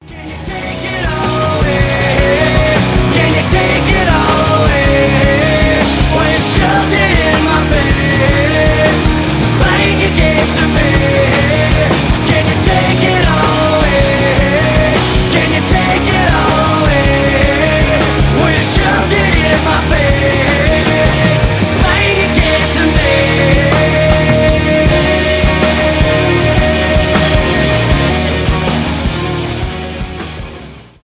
рок , post-grunge
alternative rock